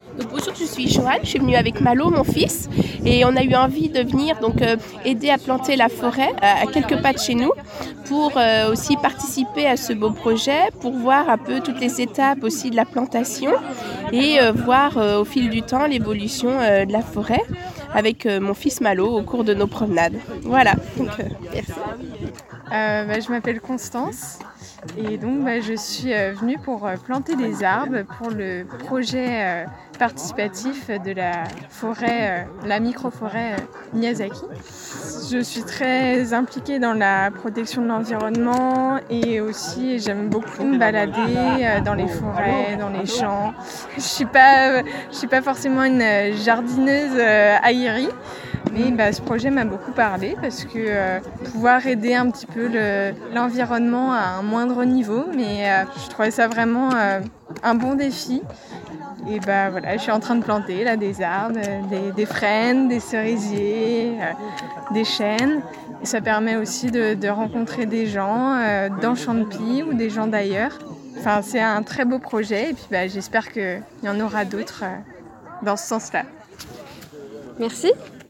par habitantes de Chantepie